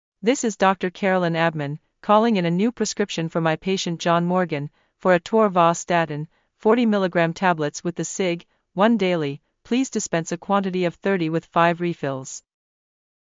Practice Taking Verbal Prescriptions